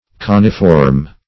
Coniform \Co"ni*form\, a.
coniform.mp3